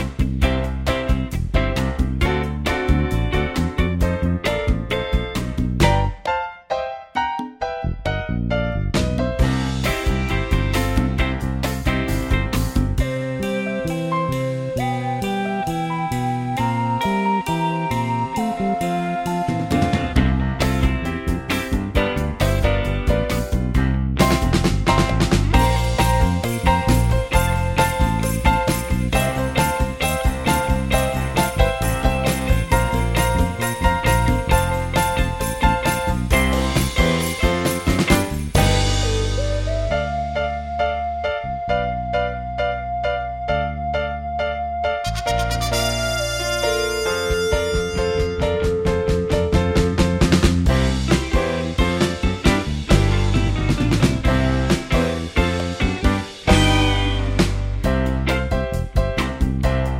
no Backing Vocals Soft Rock 2:49 Buy £1.50